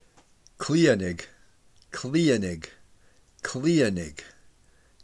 Pronounciation
Clee-a-naig